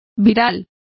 Complete with pronunciation of the translation of viral.